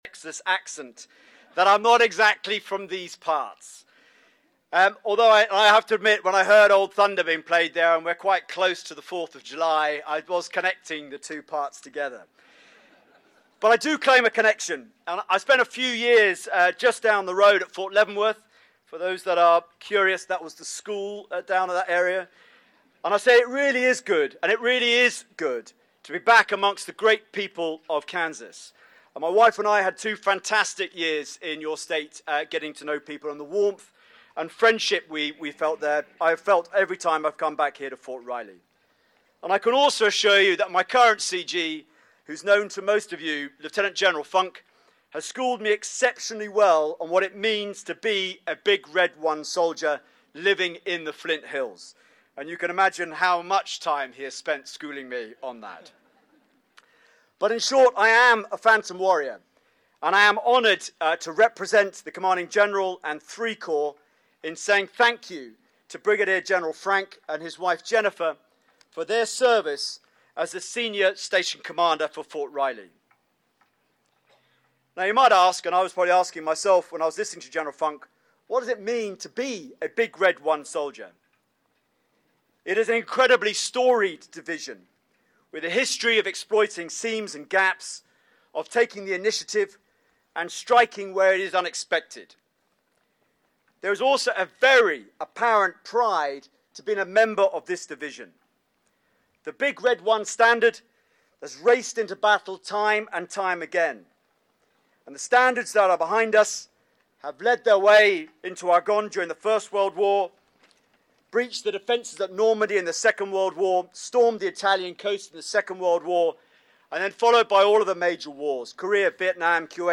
Departing commander honored at Fort Riley Tuesday